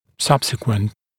[‘sʌbsɪkwənt][‘сабсикуэнт]последующий, дальнейший